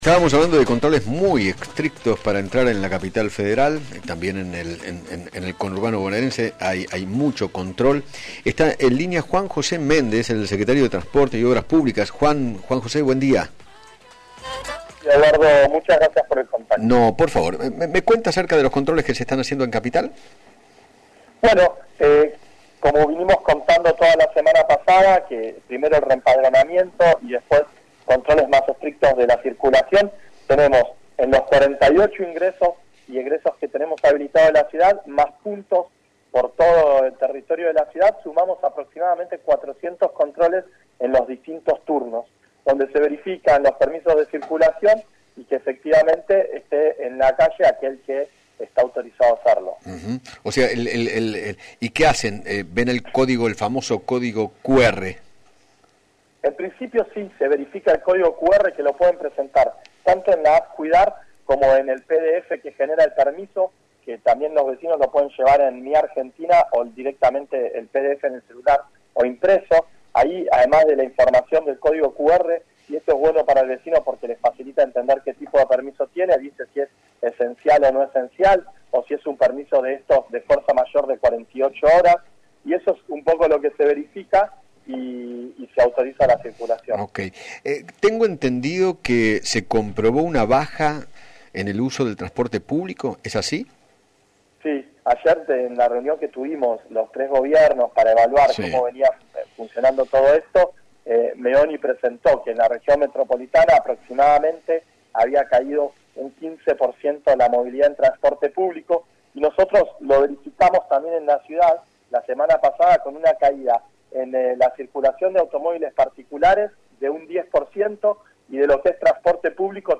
Juan José Méndez, Secretario de Transporte y Obras Públicas del Gobierno de la Ciudad, dialogó con Eduardo Feinmann sobre el uso del transporte público y particular durante la cuarentena, y se refirió a los controles que están llevando adelante en el ingreso a CABA.